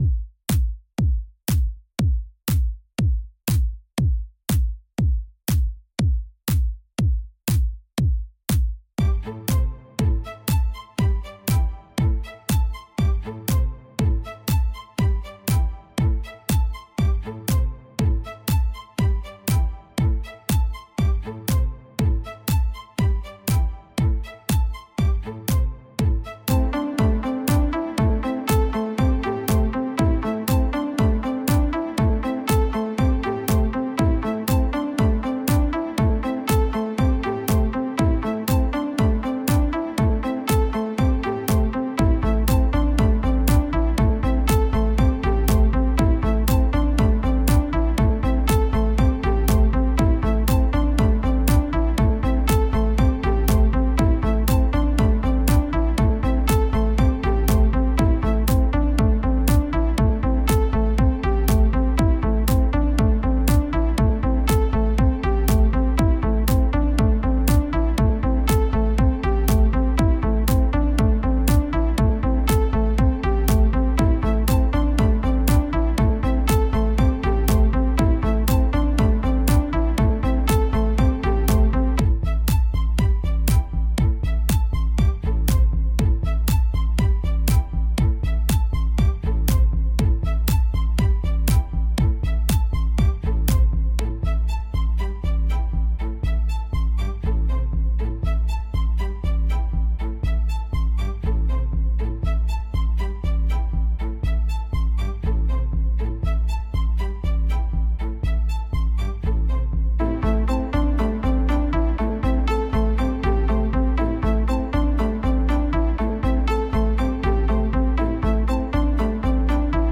Žánr: Electro/Dance
Elektronika se snoubí s klasickou hudbou